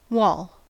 Pronunciation of the term in US English
En-us-wall.ogg.mp3